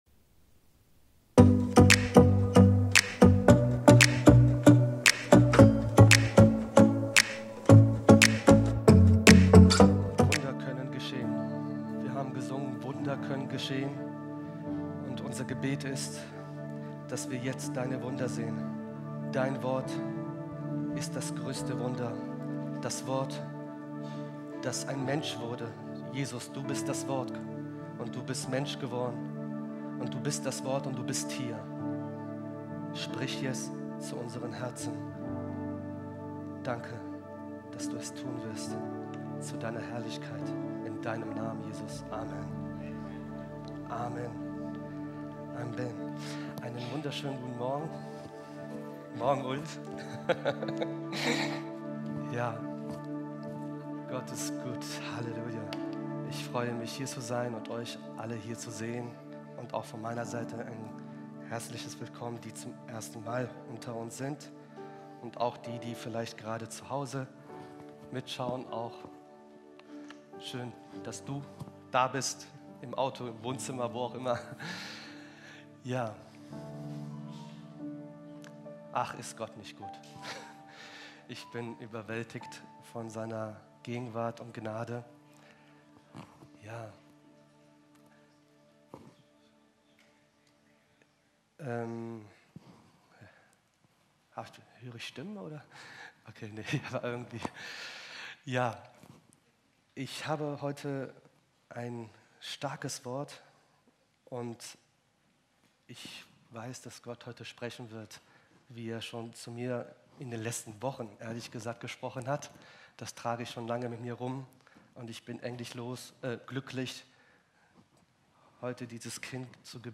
Video und MP3 Predigten
Kategorie: Sonntaggottesdienst Predigtserie: Church on fire